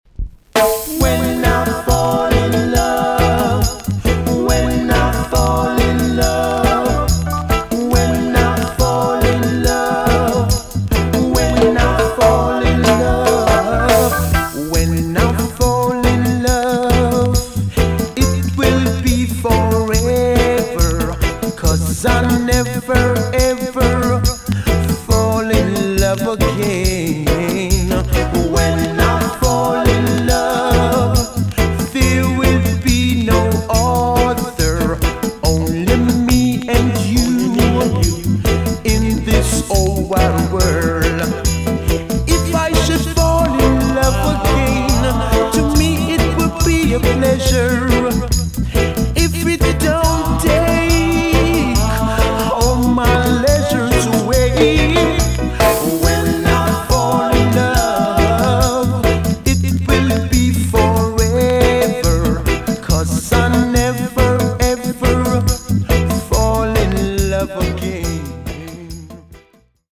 VG+ 少し軽いチリノイズがありますが良好です。